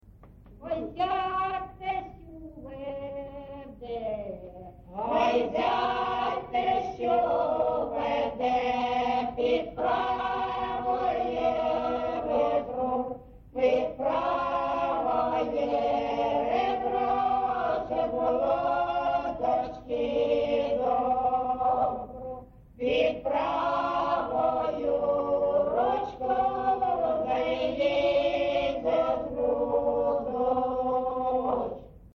ЖанрВесільні
Місце записус. Маринівка, Шахтарський (Горлівський) район, Донецька обл., Україна, Слобожанщина